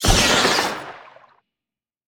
Sfx_creature_spinner_death_01.ogg